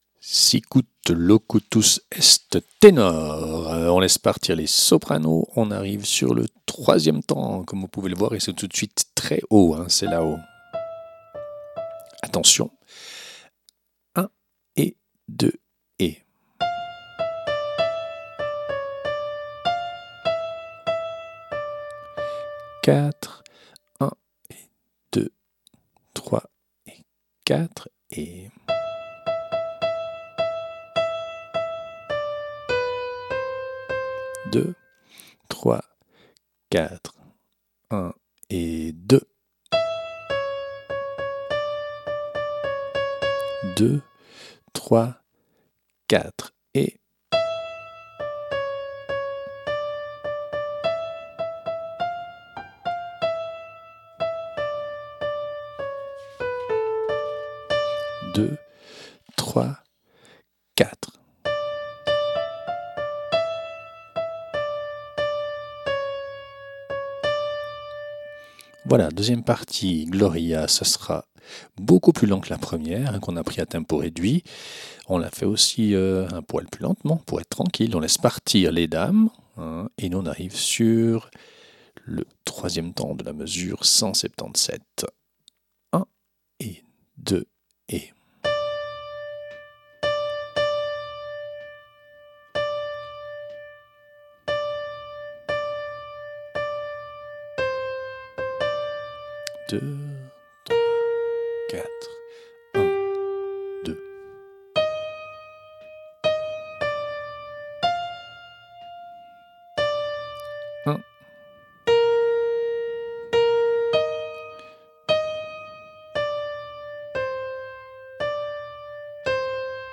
Répétition SATB4 par voix
Ténor
Sicut Locutus est Tenor.mp3